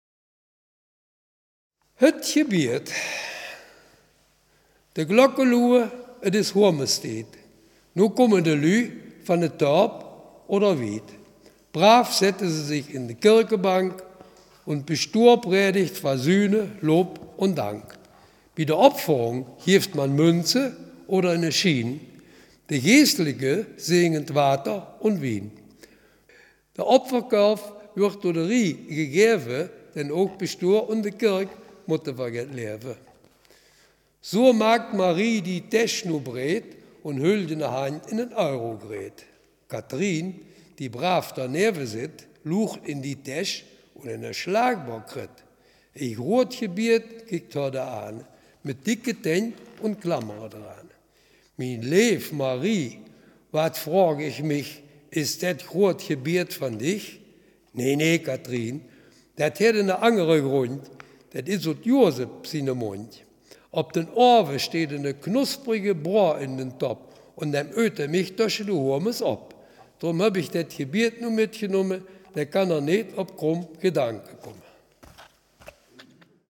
Selfkant-Platt
Gedicht